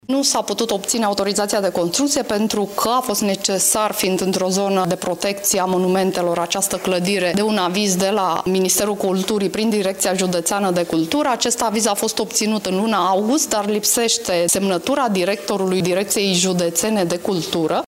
Situație și mai gravă este la Spitalul de Psihiatrie şi Neurologie, din Centrul Vechi, unde s-au finalizat lucrările de reparaţii şi zugrăveli în interiorul clădirii, și ar urma să fie efectuate cele la acoperiş. Dar, din nou lipsește aprobarea directorului de la Cultură, spune consilierul județean, Mariana Cîju.